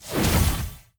melee2.wav